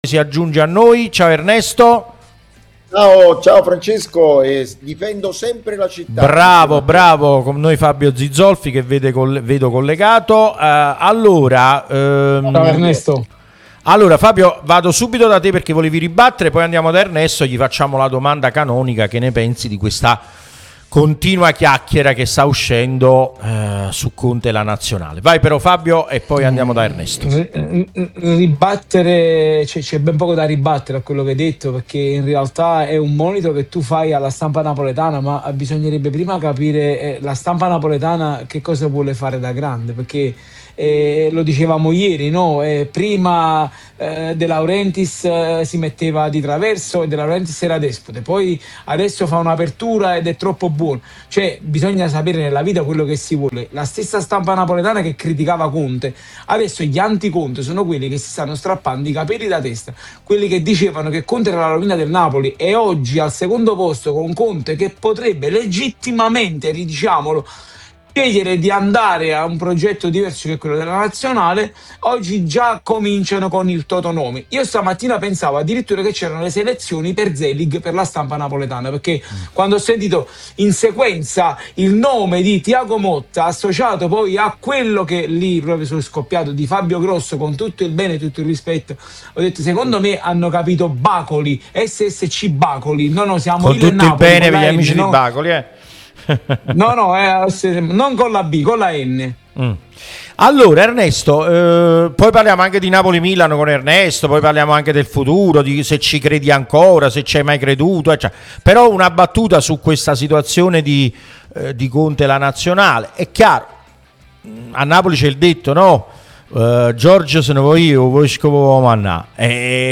Intervista Radio Tutto Napoli: il giornalista ed ex europarlamentare Caccavale su Conte, Nazionale e futuro Napoli e lotta scudetto.